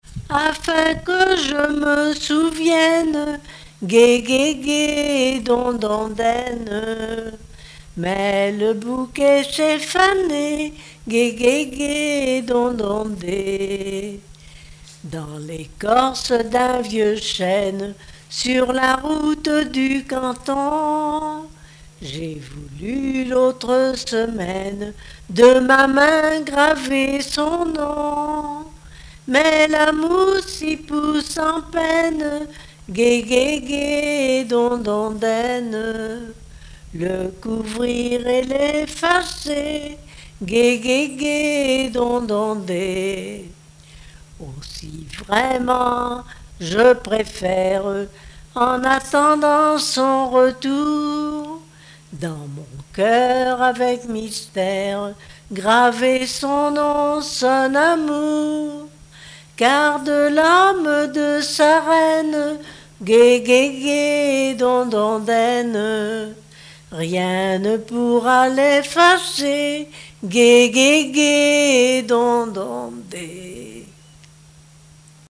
Genre strophique
Répertoire de chansons de variété
Pièce musicale inédite